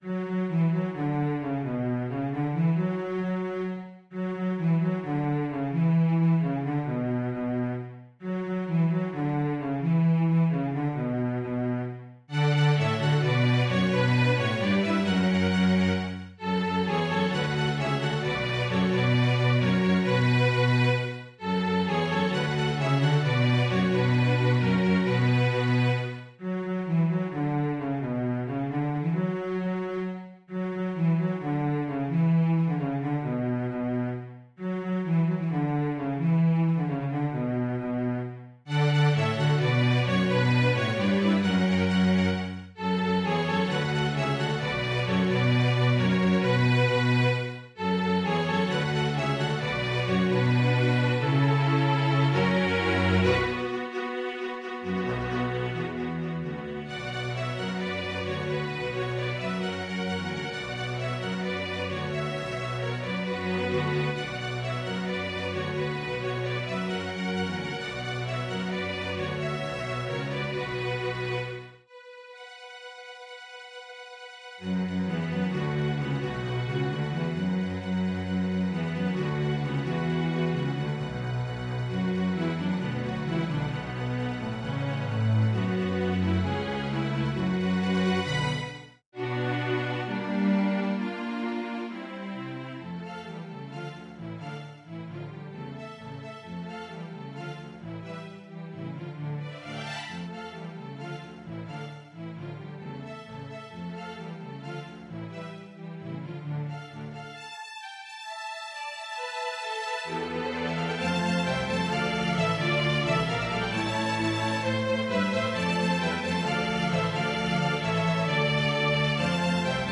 Composer: Spanish Carol
Voicing: String Orchestra